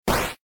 flame.mp3